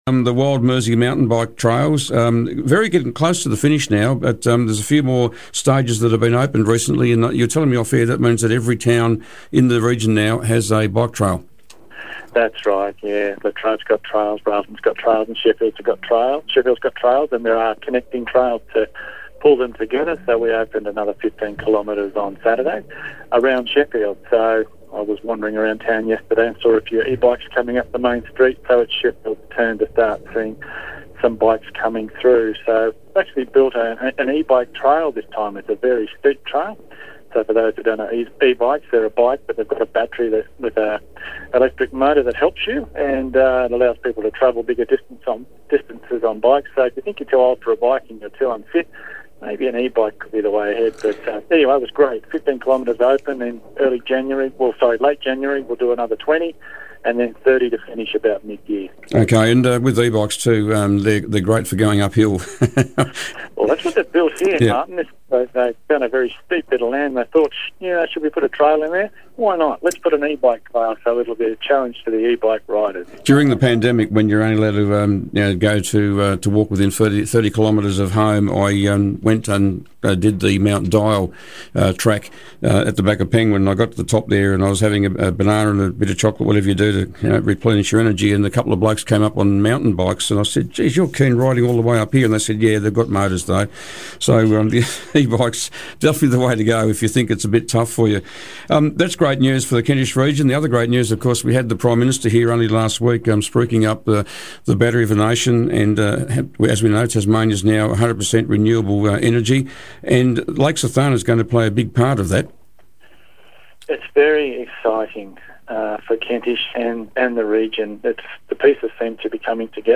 Kentish Mayor Tim Wilson was today's Mayor on the Air.